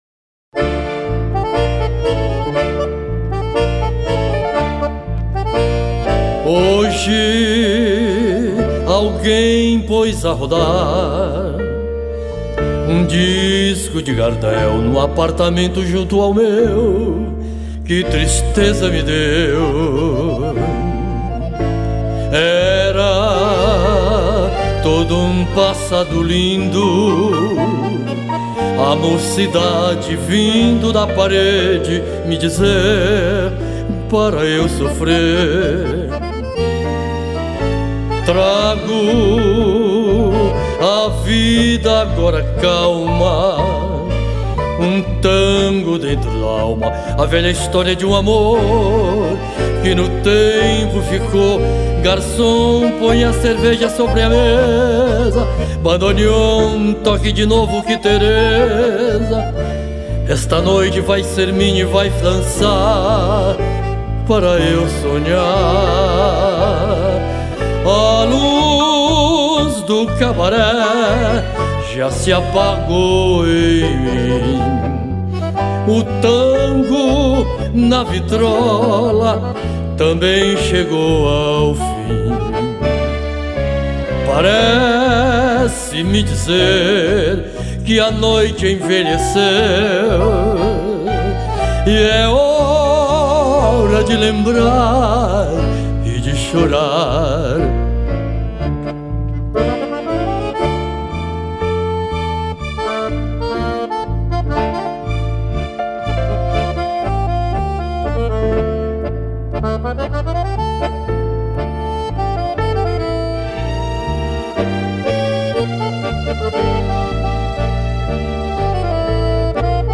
2286   03:51:00   Faixa:     Tango